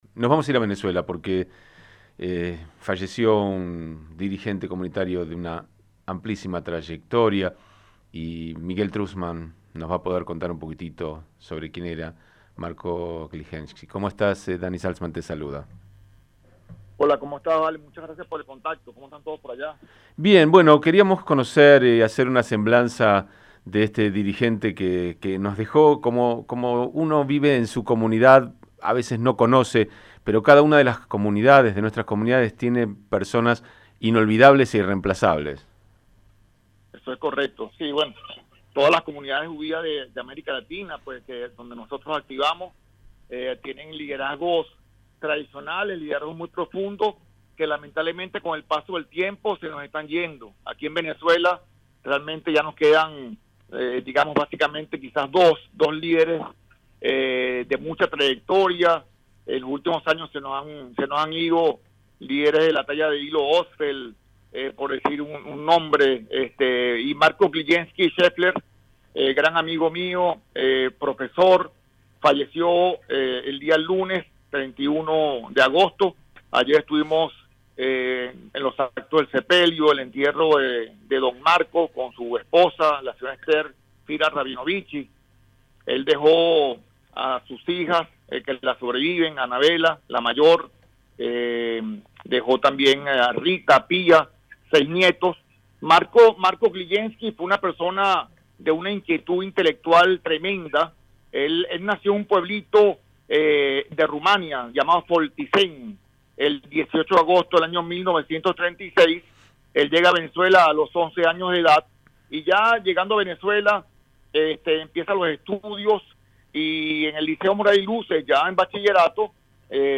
Conversamos